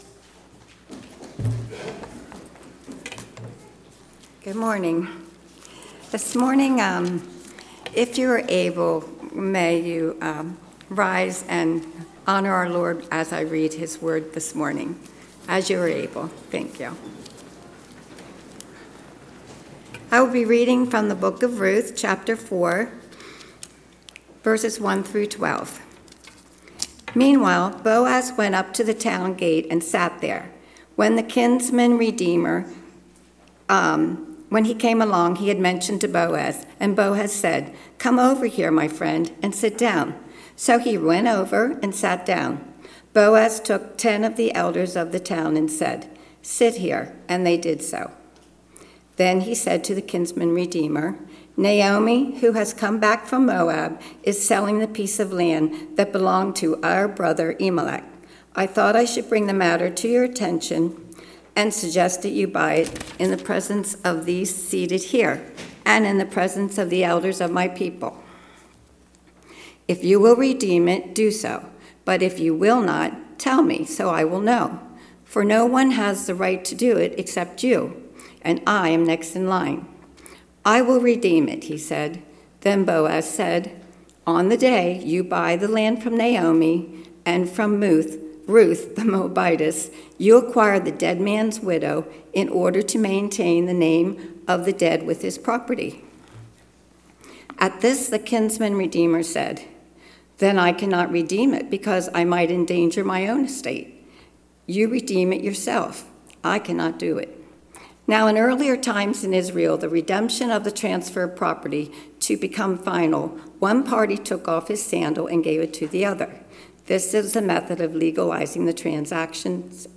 A message from the series "August 2025."